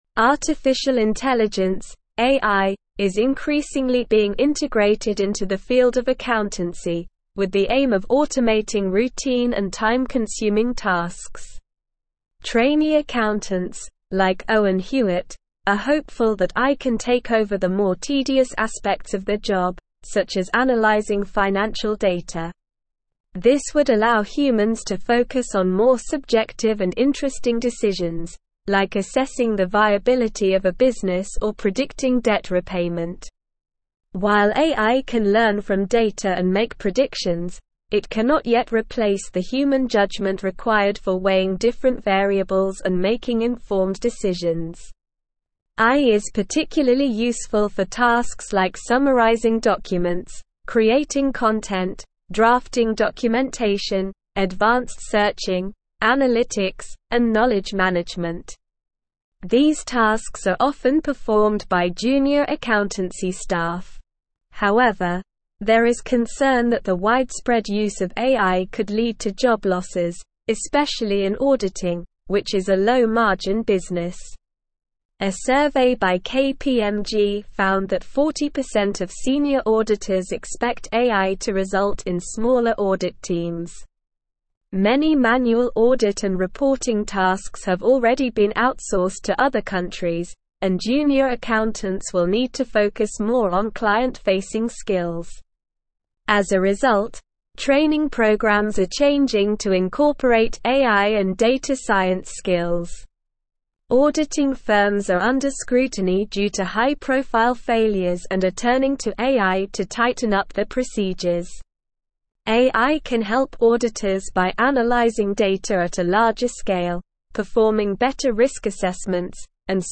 Slow
English-Newsroom-Advanced-SLOW-Reading-AI-Revolutionizing-Accountancy-Streamlining-Processes-and-Improving-Audits.mp3